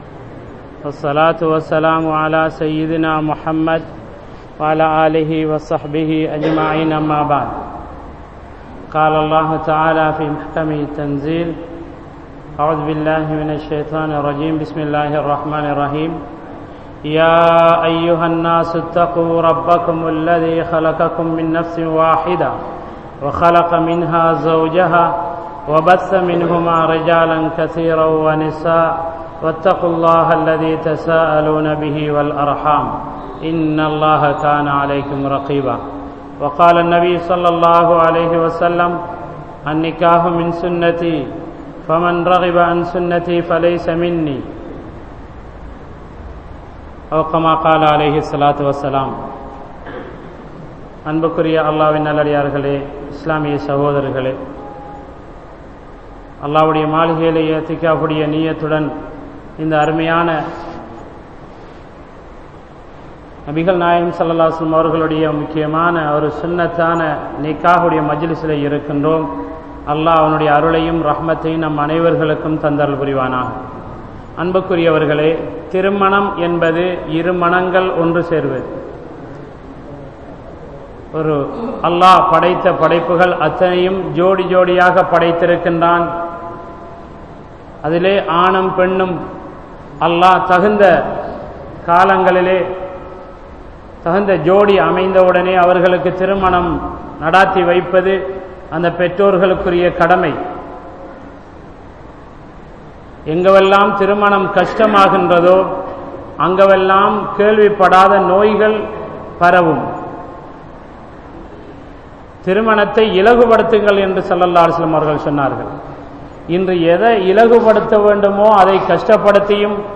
Manaiviel Nimmathi Undu (மனைவியில் நிம்மதி உண்டு) | Audio Bayans | All Ceylon Muslim Youth Community | Addalaichenai
Grand Jumua Masjith